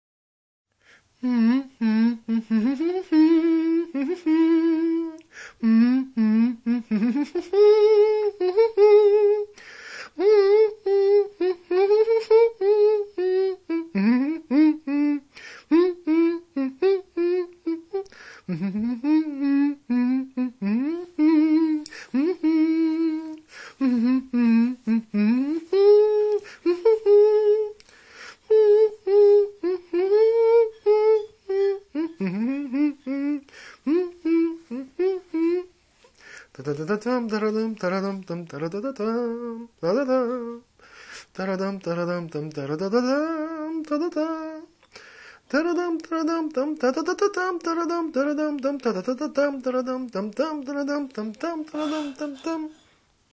Постарался ее фрагмент изобразить голосом.